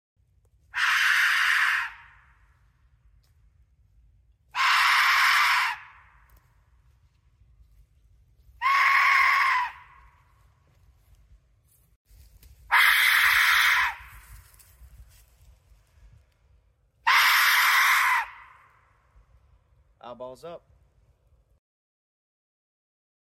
Glow in the Dark Aztec Death Whistle